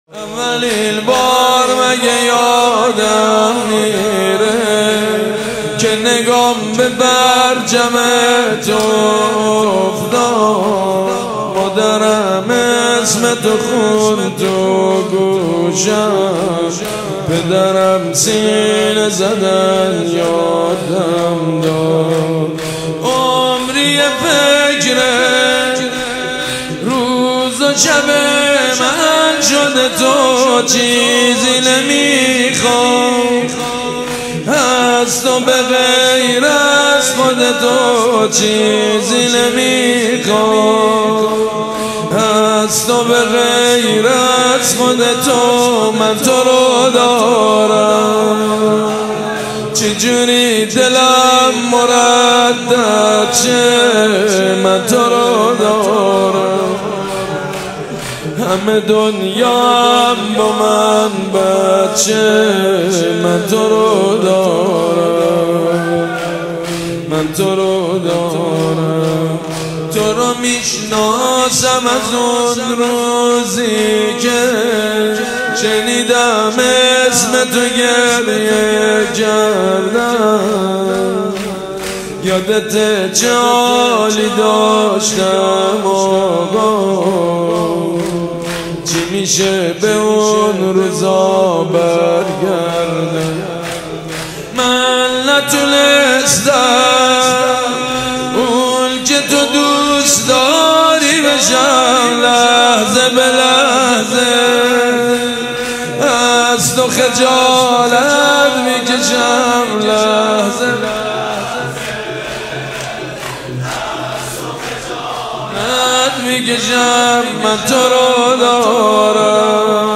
سید مجید بنی فاطمه | دفتر آیت الله علوی بروجردی | فاطمیه 1441